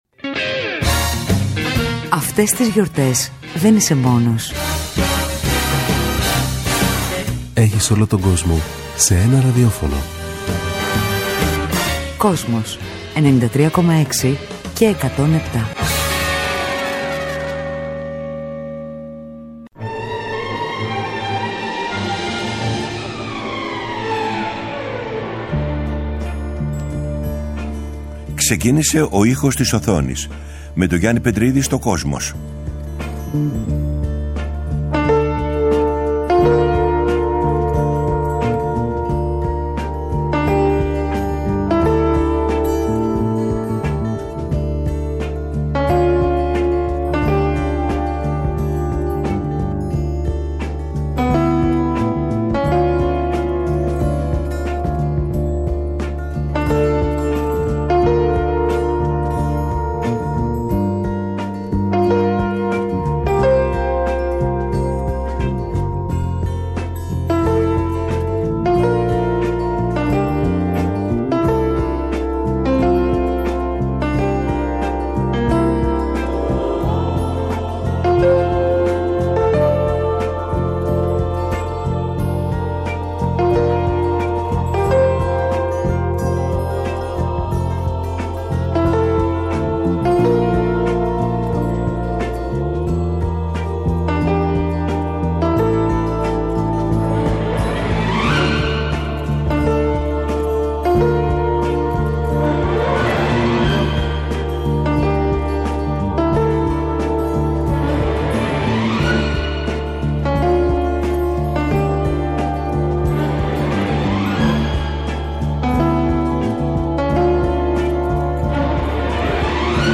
Κάθε Κυριακή 18:00-19:00 ο Γιάννης Πετρίδης παρουσιάζει μία σειρά αφιερωματικών εκπομπών για το Kosmos, με τον δικό του μοναδικό τρόπο.